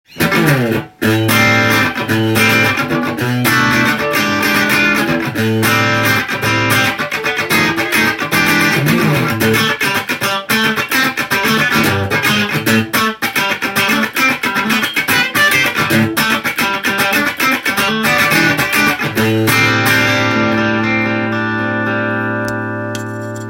このエフェクターは歪系のオーバードライブで
アタッチ感が強い音がしました。
ジャキジャキした音がするので